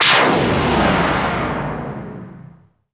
HITSWEEP.wav